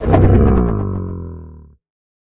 25w18a / assets / minecraft / sounds / mob / ravager / stun2.ogg
stun2.ogg